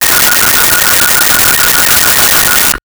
Telephone Ring 01
Telephone Ring 01.wav